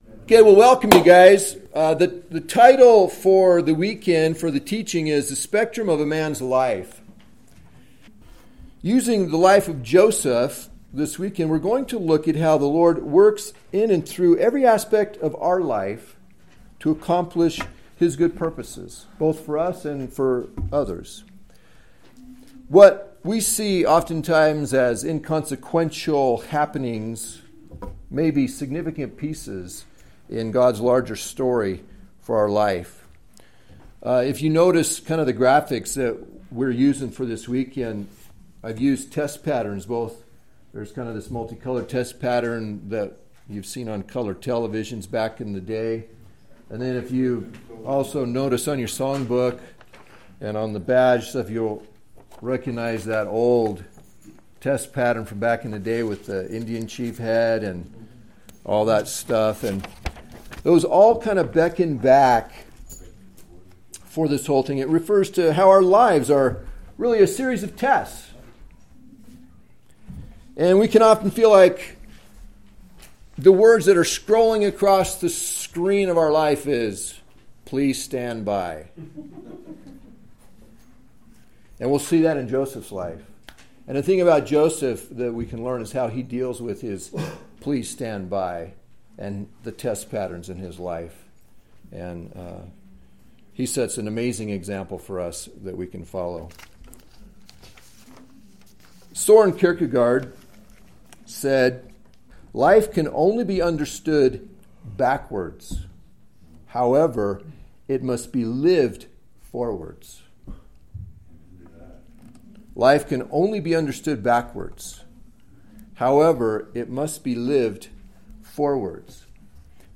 Teachings from Men's meetings and retreats.